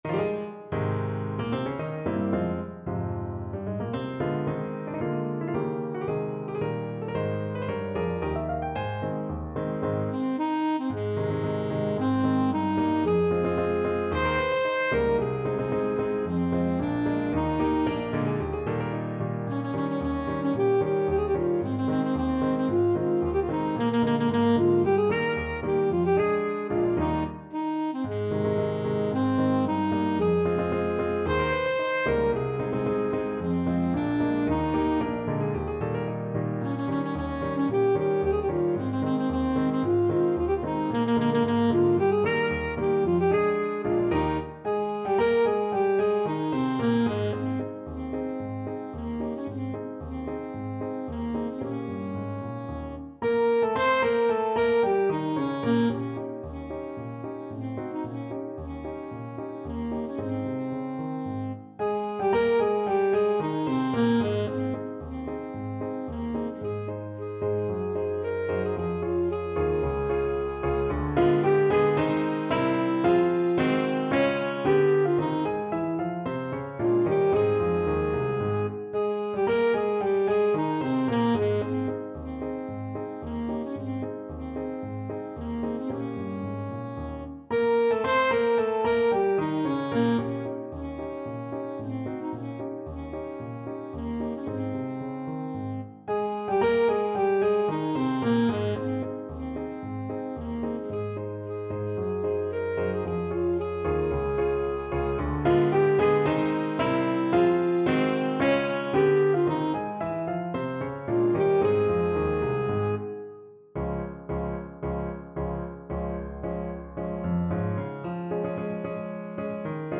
Alto Saxophone
Ab major (Sounding Pitch) F major (Alto Saxophone in Eb) (View more Ab major Music for Saxophone )
2/2 (View more 2/2 Music)
March =c.112
Classical (View more Classical Saxophone Music)